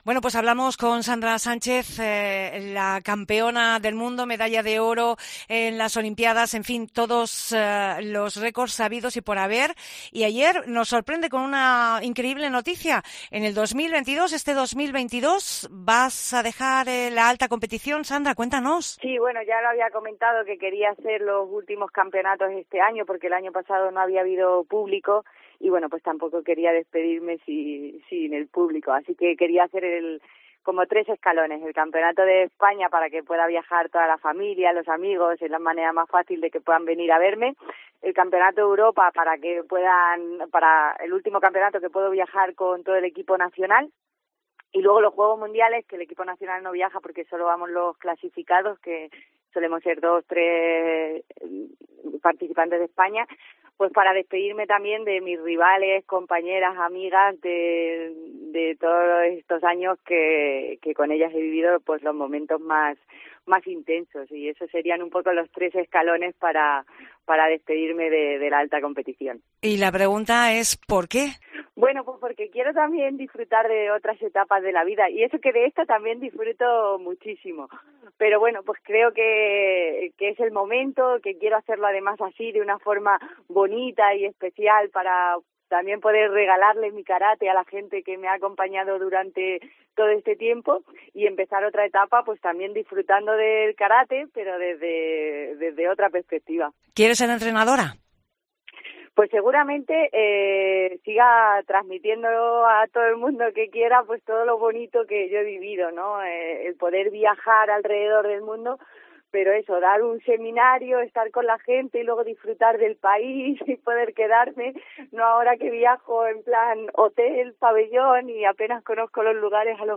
Entrevista a Sandra Sánchez que explica su retirada